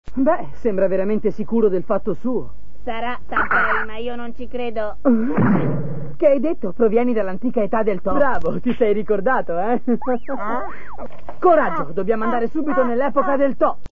nel cartone animato "La macchina del tempo", in cui doppia Tanpei.